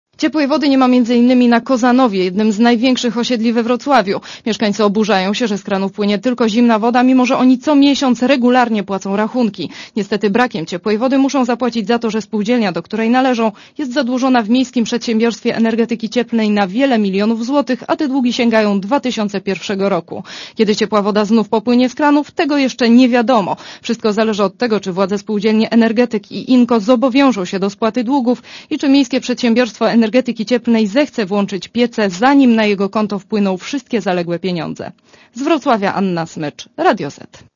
Relacja reporterki Radia Zet (148Kb)